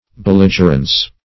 Belligerence \Bel*lig"er*ence\, Belligerency \Bel*lig"er*en*cy\,